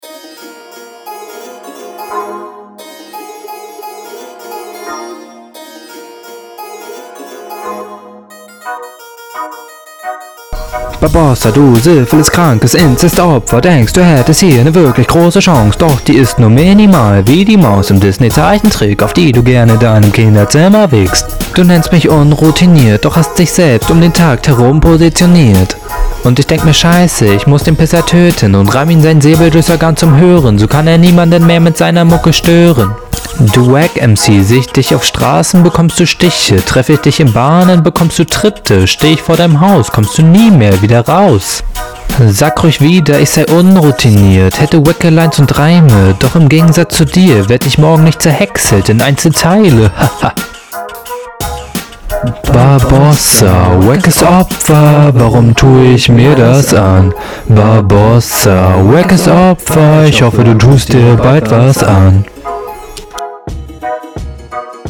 Die Qualität ist gefühlt schlechter geworden lol.